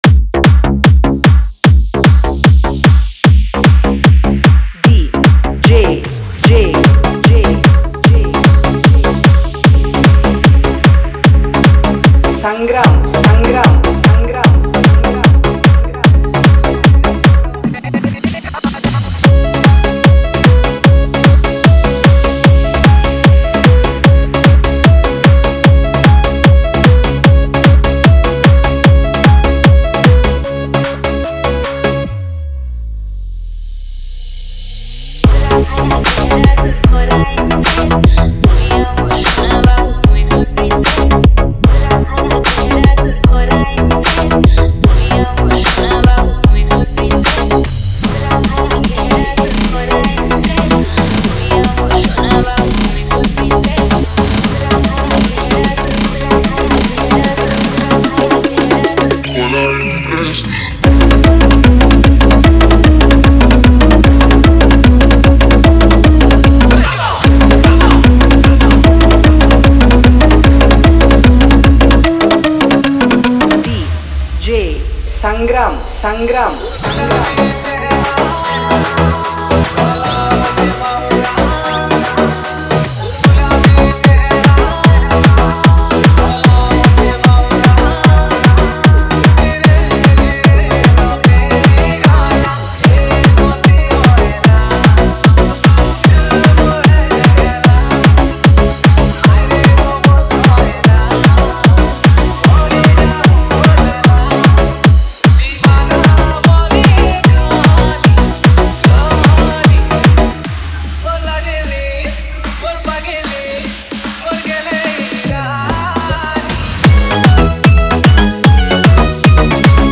Category:  New Sambalpuri Dj Song 2020